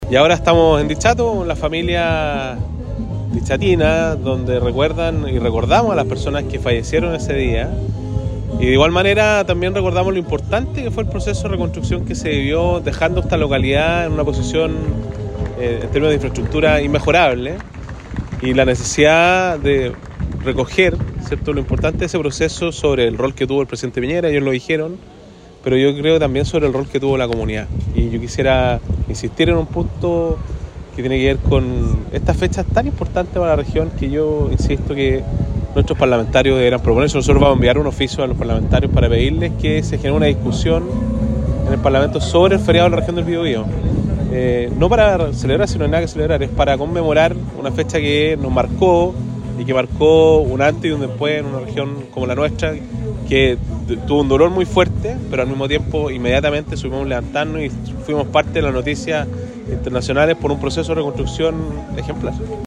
Durante la mañana de este jueves 27 de febrero, el gobernador del Biobío, Sergio Giacaman, se trasladó hasta la Plaza de la Ciudadanía de Dichato, para participar de una ceremonia de conmemoración del terremoto del 27 de febrero de 2010, organizada por el Comité de Vivienda de esa localidad.